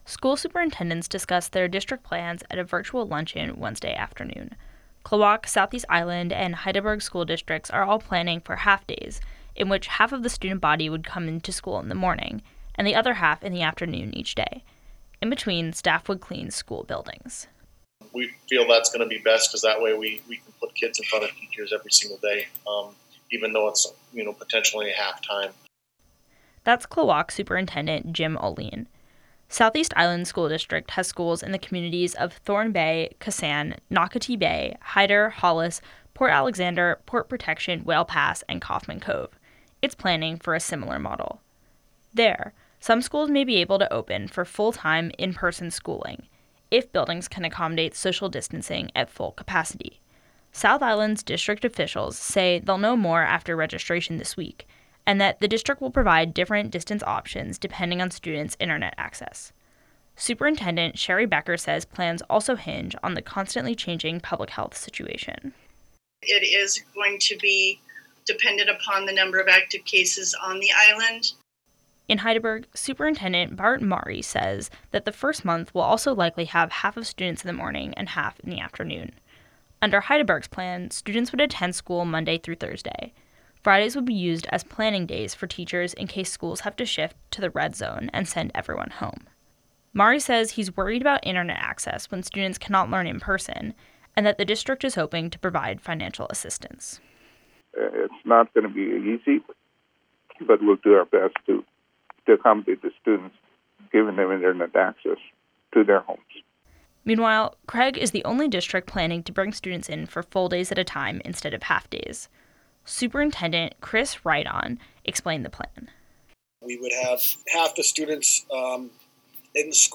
School superintendents discussed their district’s plans at a virtual luncheon Wednesday afternoon.